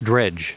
Prononciation du mot dredge en anglais (fichier audio)
Prononciation du mot : dredge